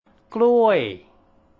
gluay [general] banana, genus Musa
정확하게 발음 하실 경우는 '끄루워이(추천) 혹은 끄루어이'